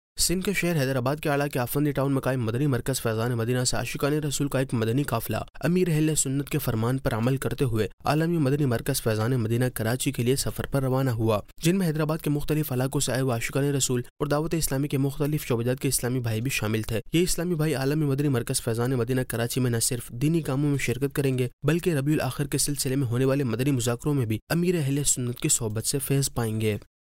News Clips Urdu - 25 October 2023 -Madani Markaz Faizan e Madina Hyderabad Se Ashiqan e Rasool Ka Madani Qaflay Mein Safar Nov 1, 2023 MP3 MP4 MP3 Share نیوز کلپس اردو - 25 اکتوبر 2023 - مدنی مرکز فیضانِ مدینہ حیدرآباد سے عاشقانِ رسول کا مدنی قافلے میں سفر